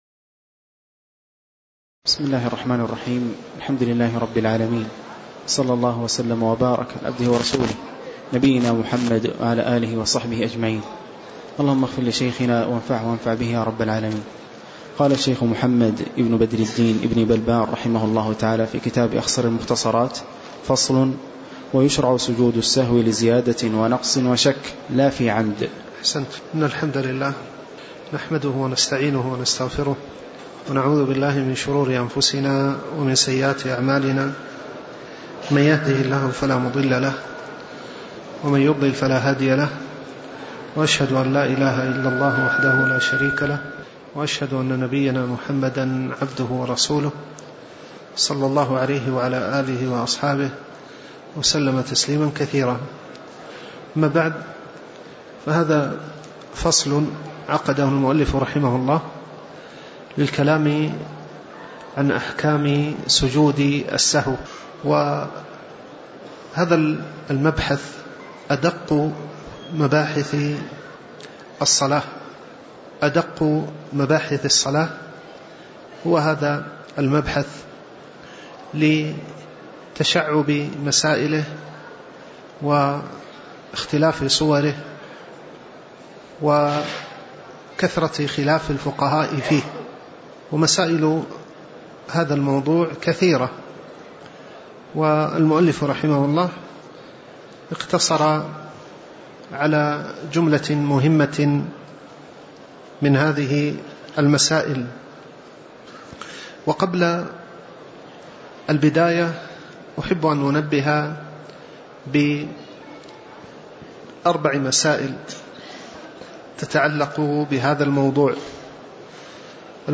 تاريخ النشر ١٢ جمادى الآخرة ١٤٣٩ هـ المكان: المسجد النبوي الشيخ